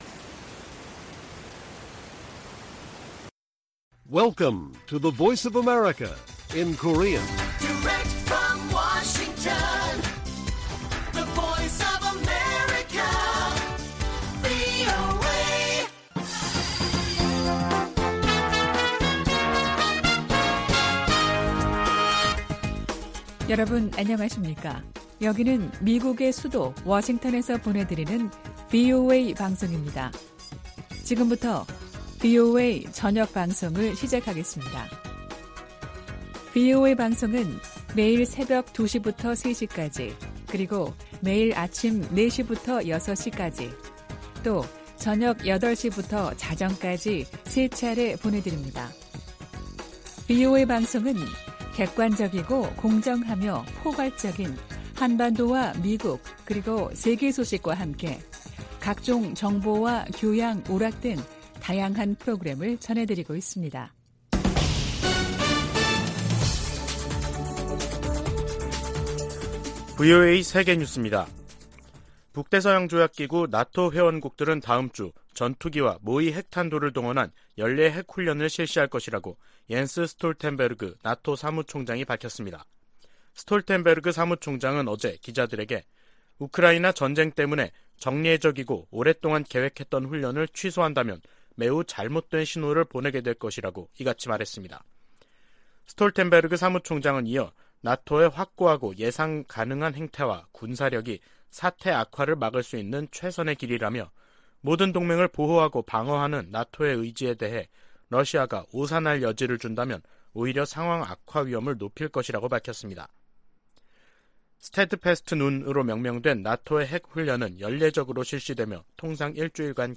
VOA 한국어 간판 뉴스 프로그램 '뉴스 투데이', 2022년 10월 12일 1부 방송입니다. 한국 일각에서 전술핵 재배치 주장이 나오는 데 대해 백악관 고위 당국자는 비핵화 목표를 강조했습니다. 북한이 최근 '전술핵 운용부대 훈련'을 전개했다며 공개한 사진 일부가 재활용된 것으로 파악됐습니다. 미국 정부가 핵을 포함한 모든 범위의 확장 억지 공약을 재확인했습니다.